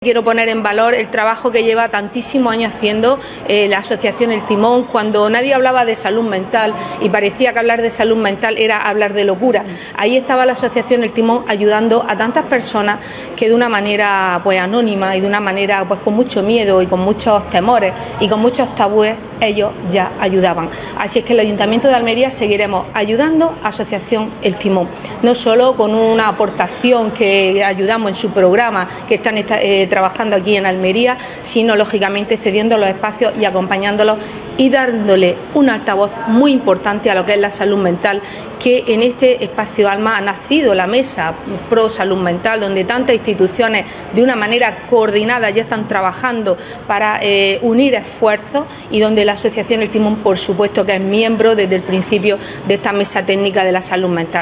PAOLA-LAYNEZ-CONCEJAL-FAMILIA-INCLUSION-E-IGUALDAD.wav